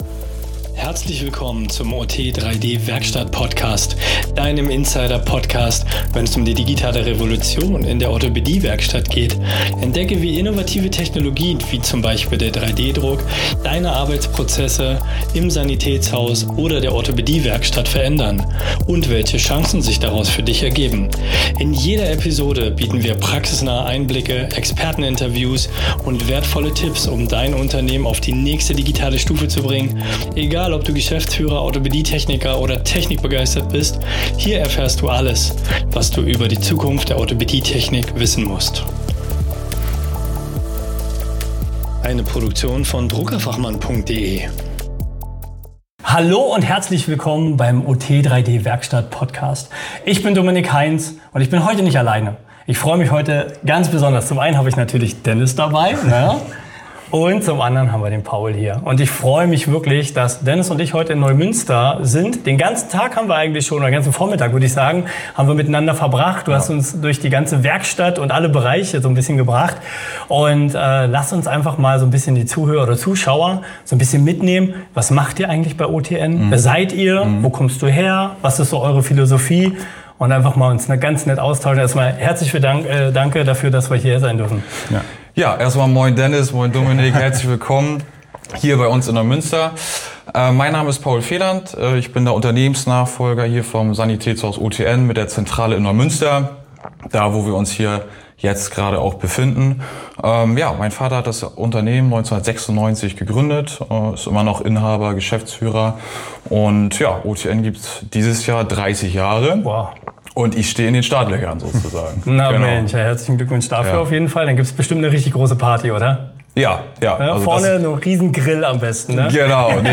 In diesem Interview tauchen wir in die Welt eines Familienbetriebs im Gesundheitswesen ein.